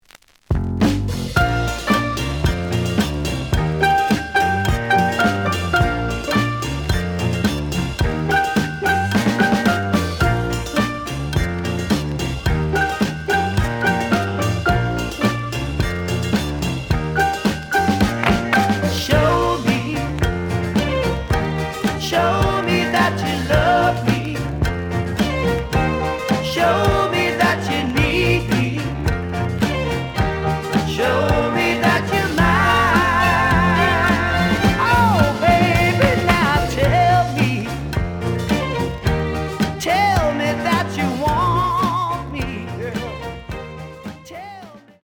試聴は実際のレコードから録音しています。
The audio sample is recorded from the actual item.
●Genre: Soul, 70's Soul
Slight edge warp.